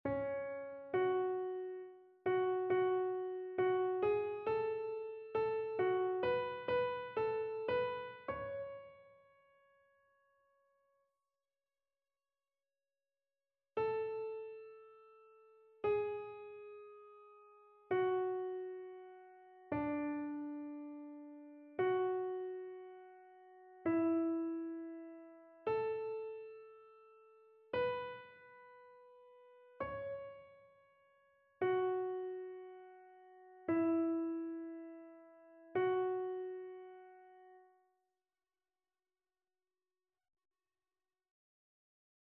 Chœur
annee-b-temps-du-careme-3e-dimanche-psaume-18-soprano.mp3